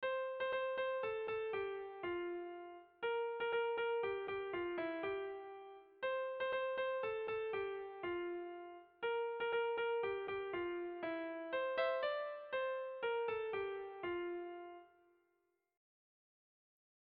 Dantzakoa
Hondarribia < Bidasoaldea < Gipuzkoa < Euskal Herria
Lauko berdina, 3 puntuz eta 8 silabaz (hg) / Hiru puntuko berdina (ip)
8A/8A/8/8A/